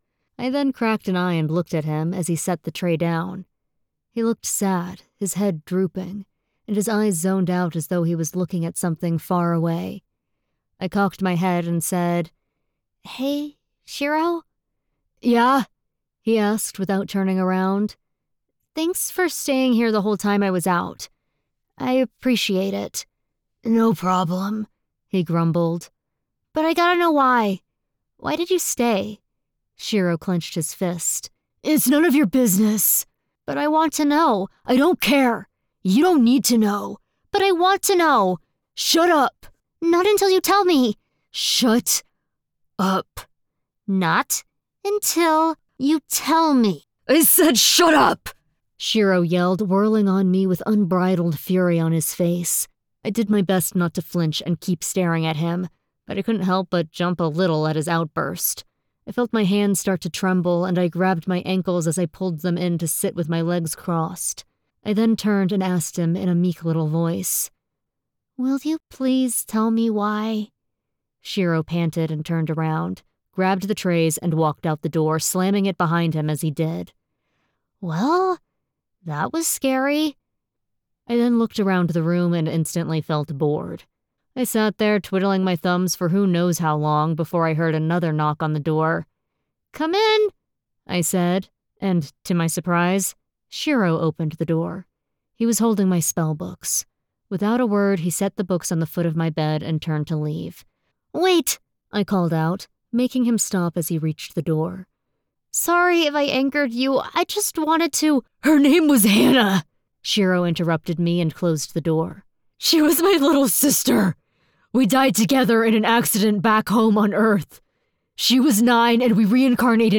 1st Person LitRPG